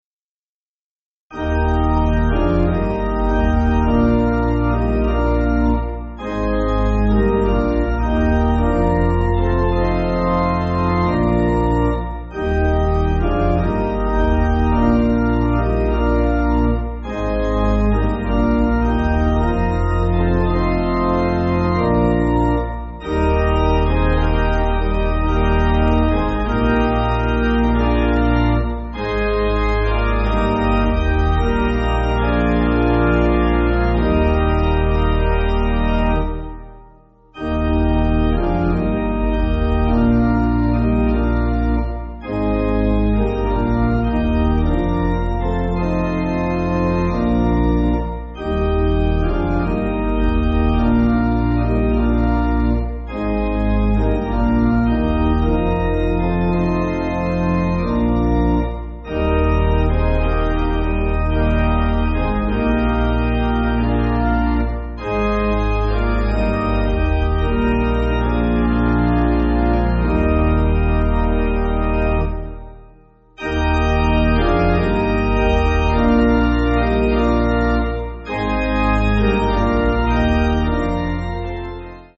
(CM)   3/Eb